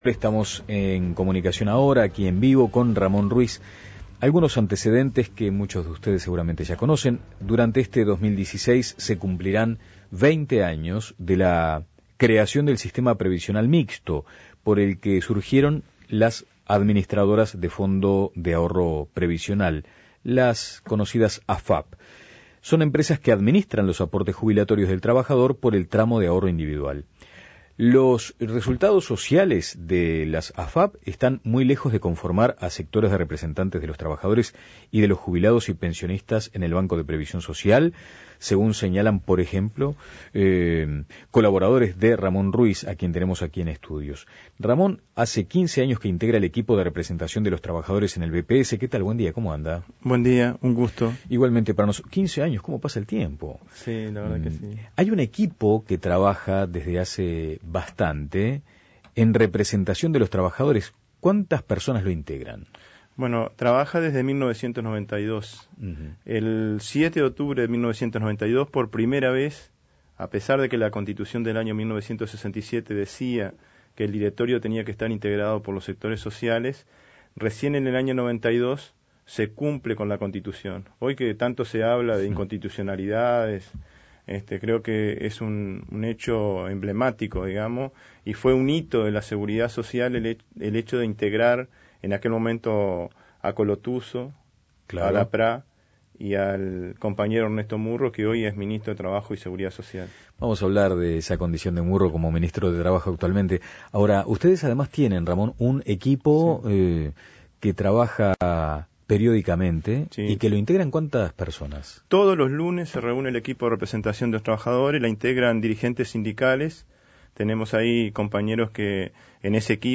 Entrevista a Ramón Ruíz